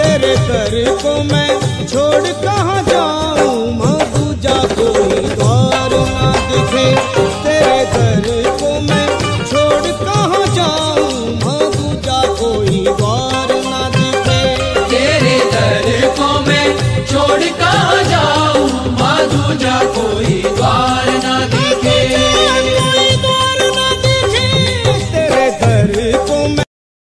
Enjoy the peaceful and devotional tone on your mobile today.